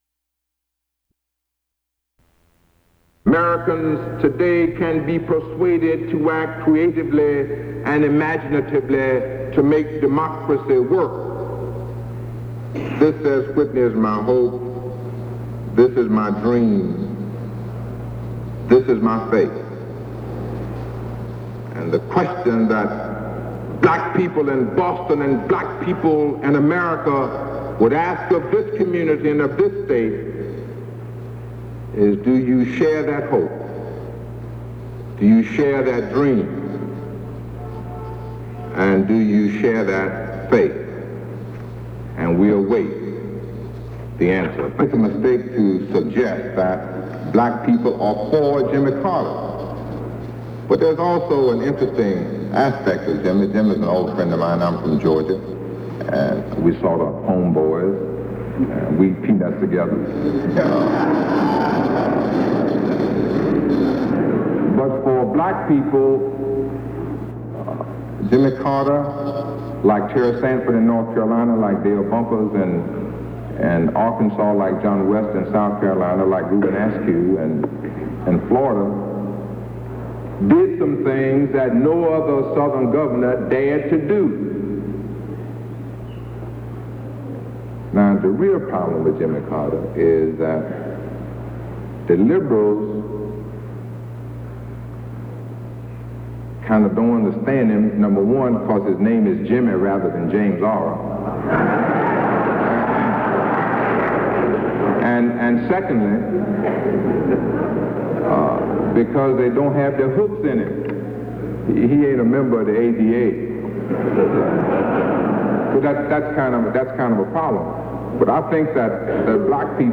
Subjects Carter, Jimmy, 1924- African Americans--Civil rights Material Type Sound recordings Language English Extent 00:05:39 Venue Note Broadcast 1976 June 2.